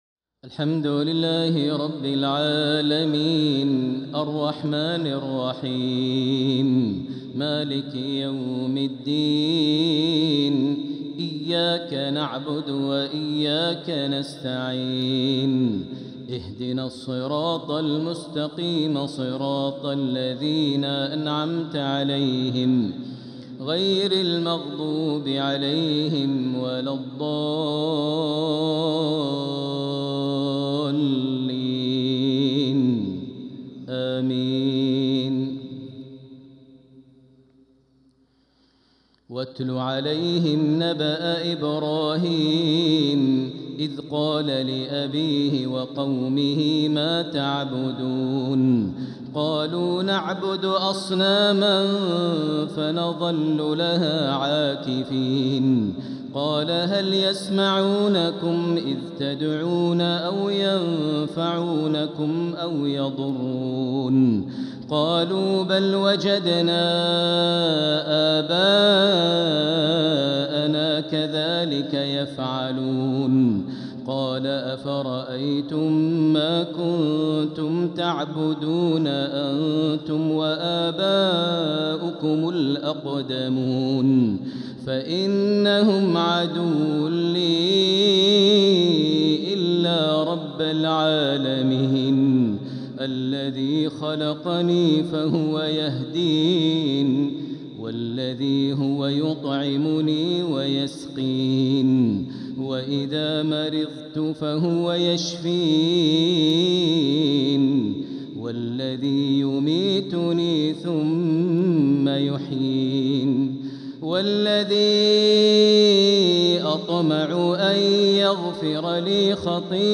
Maghrib prayer from Surat Ash-shu'ara 69-104 | 6-5-2025 > 1446 H > Prayers - Maher Almuaiqly Recitations